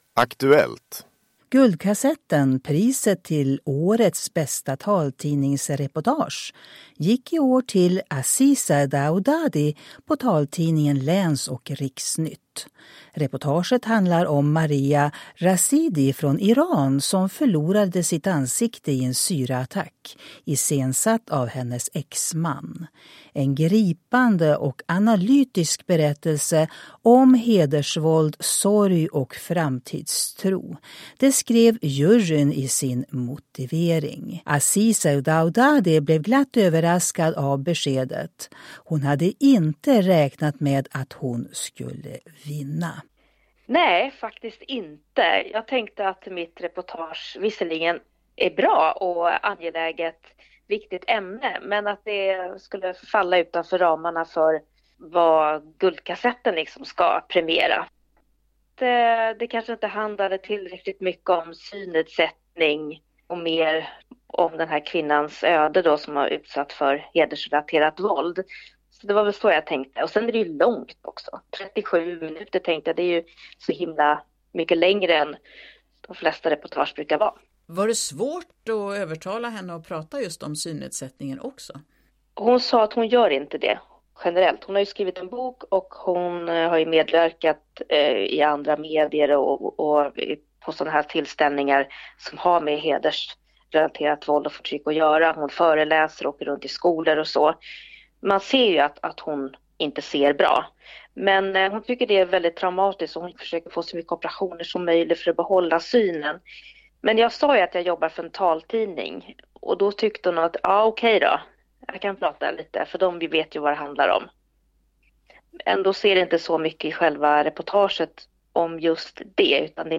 Guldkassetten till årets bästa taltidningsreportage